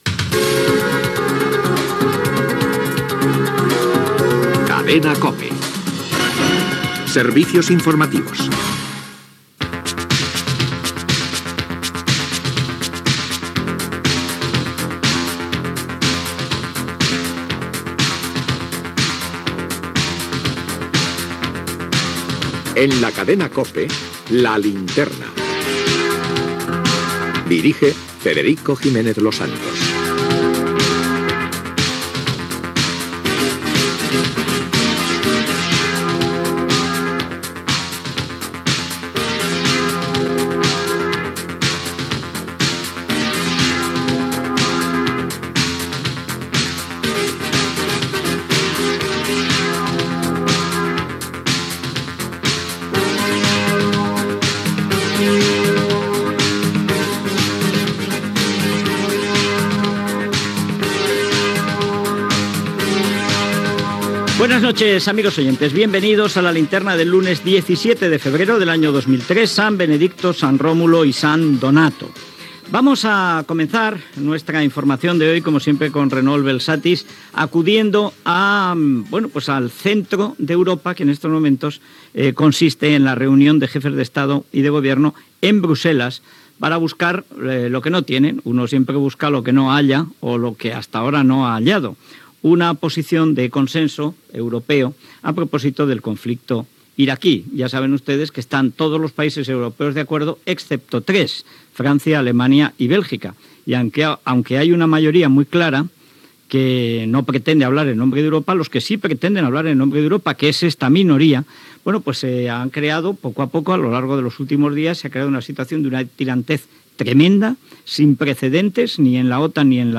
Careta del programa, data,informació de la reunió de presidents de govern europeus, a Brusel·les, per tractar del conflicte d'Iraq
Informatiu